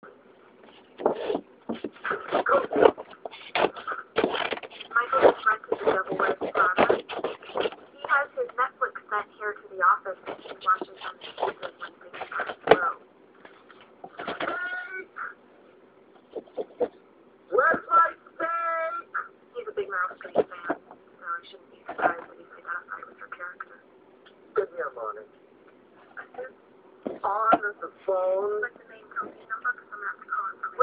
Field Recording
Sounds Heard: Cutting through paper, ‘The Office’ playing, coughing, vicious watercoloring